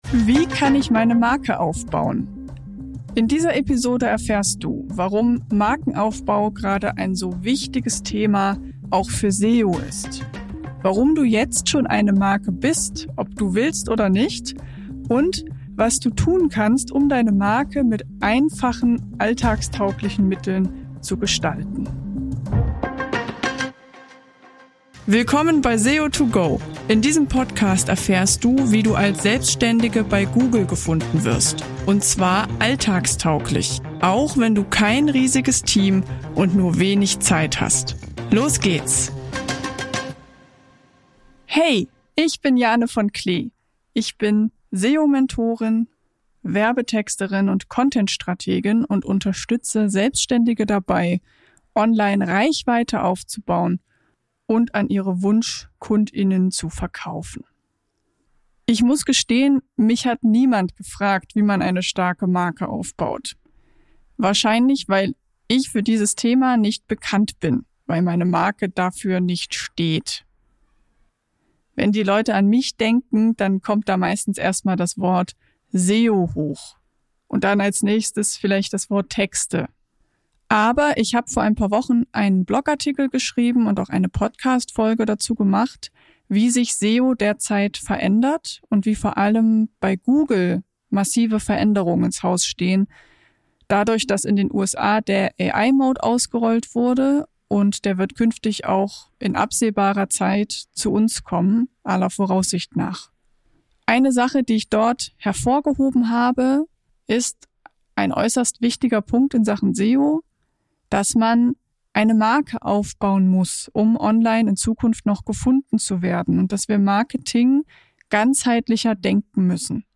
Unaufgeregt, empathisch und mit bildlichen Beispielen führt sie durch den SEO-Dschungel und zeigt: In Wirklichkeit ist er gar nicht so verworren wie sein Ruf.